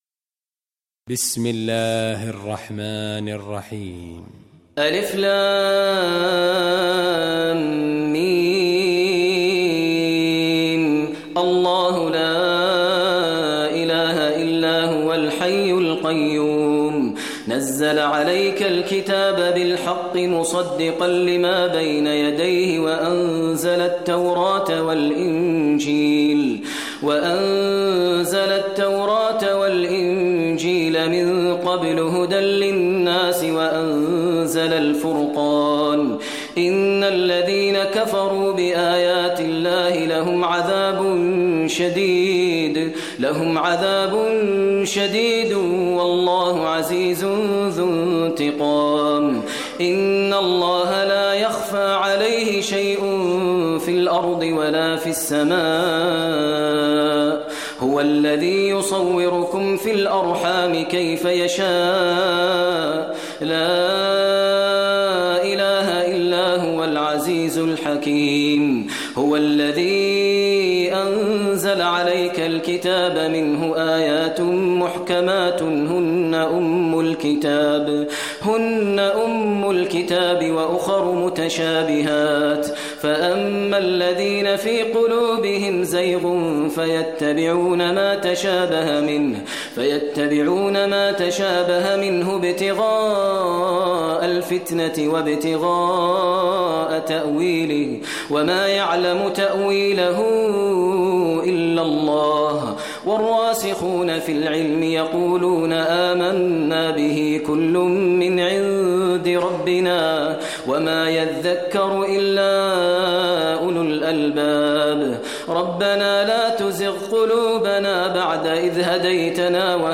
Surah Imran Recitation by Maher al Mueaqly
Surah Imran is 3rd chapter of Holy Quran. Listen online mp3 tilawat / recitation in Arabic in the voice of Imam e Kaaba Sheikh Maher al Mueaqly.